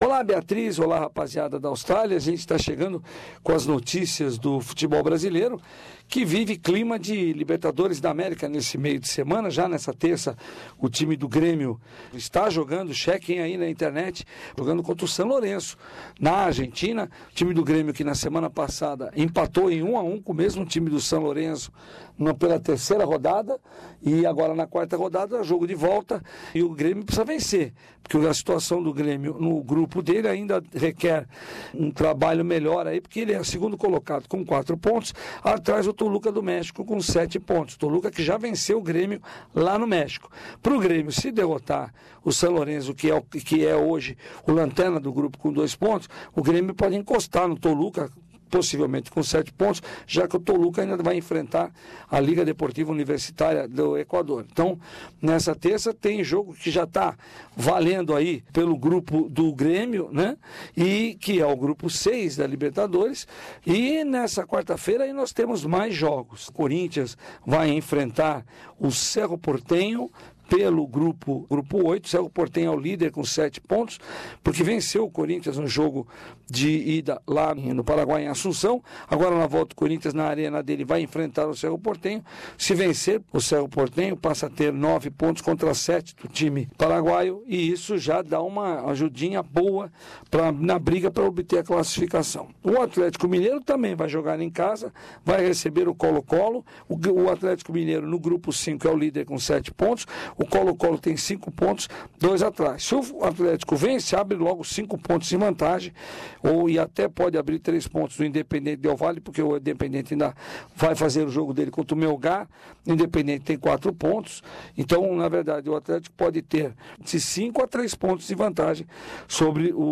Libertadores da América: as chances dos brasileiros na rodada deste meio de semana. E suspeita de caso de doping no atletismo pode tirar chance de medalha do revezamento 4 x 100 feminino do Brasil. Boletim semanal do esporte brasileiro